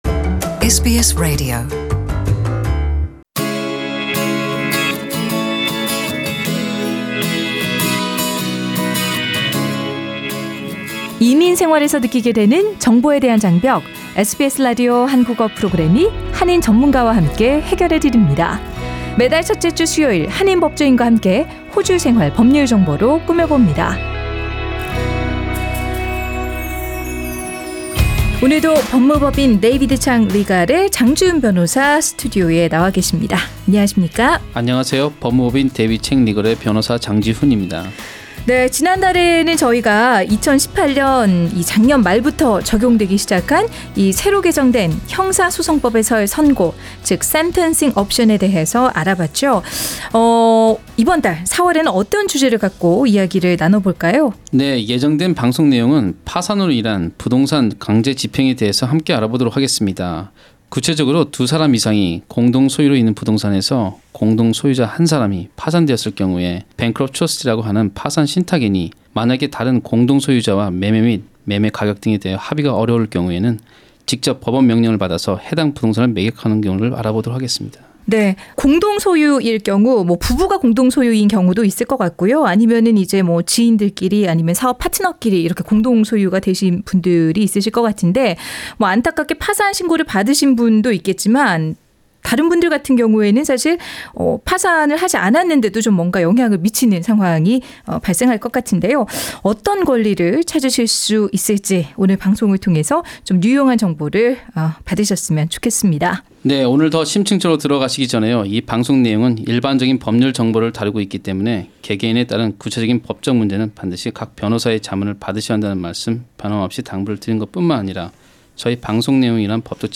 [The Full interview is available via podcast above]